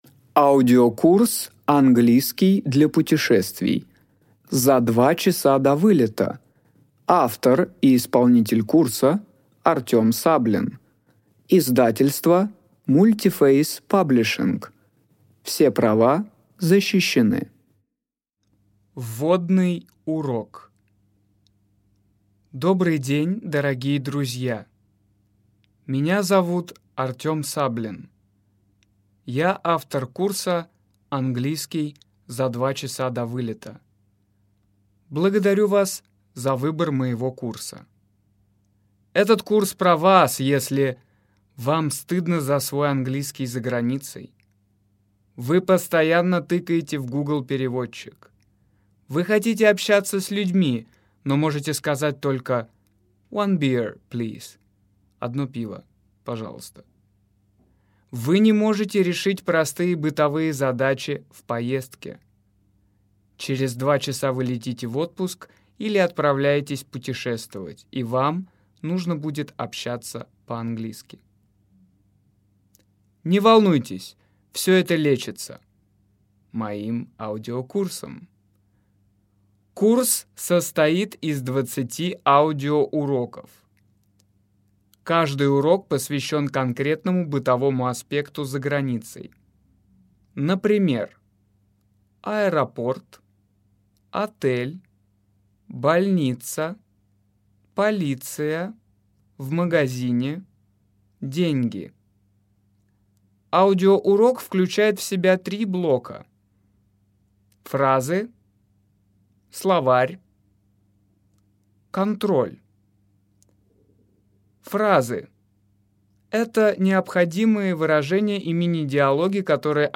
Аудиокнига Урок 13: Полиция | Библиотека аудиокниг